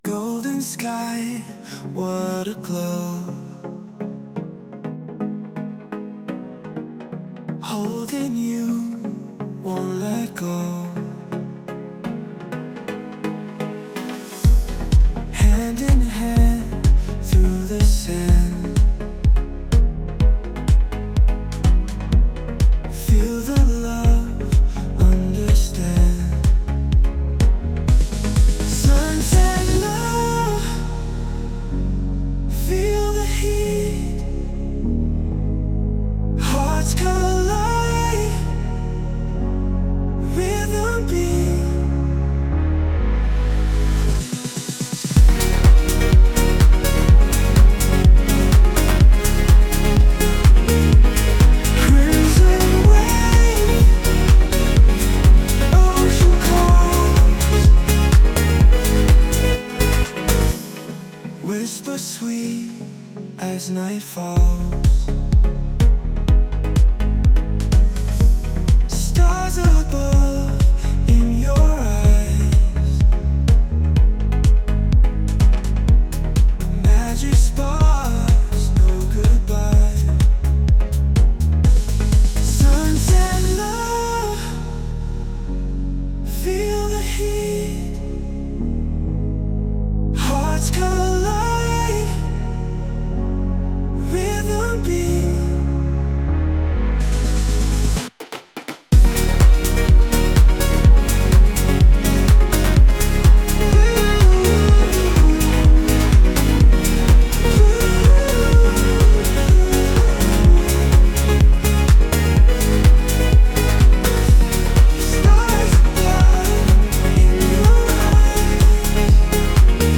Вы как минимум можете с помощью AI делать вокальные версии.